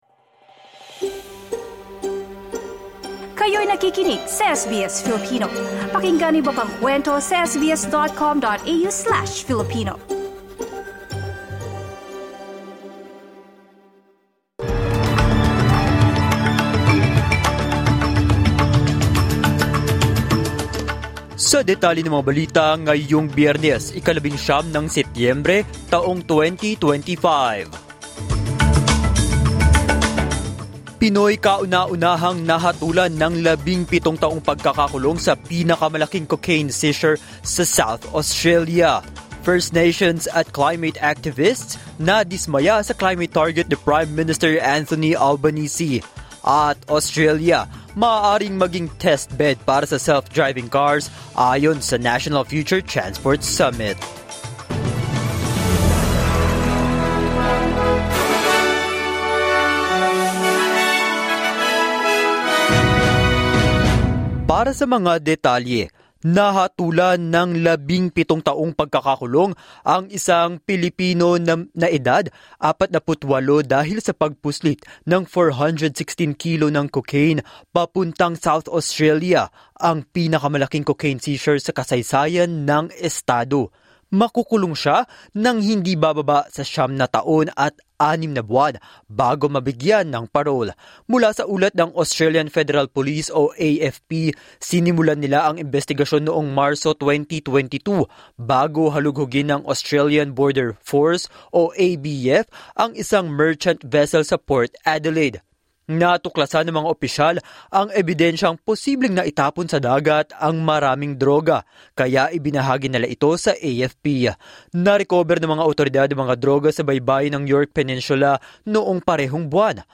SBS News in Filipino, Friday 19 September 2025